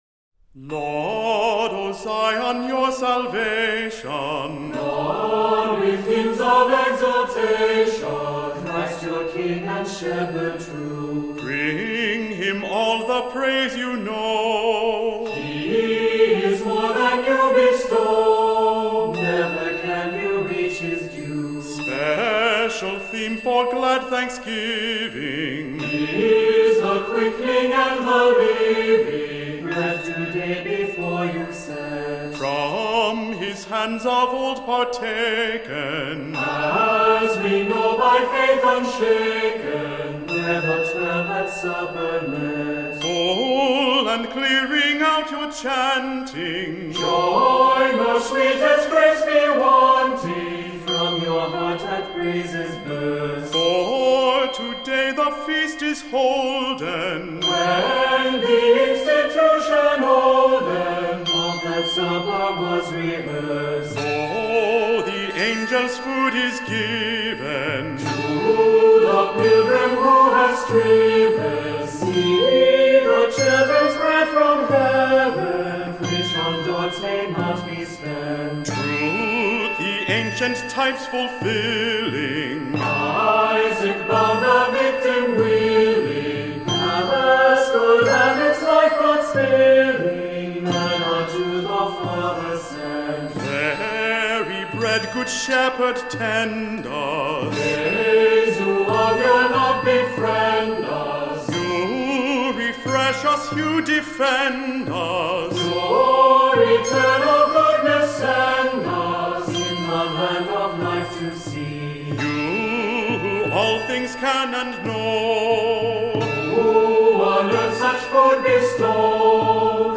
Voicing: Cantor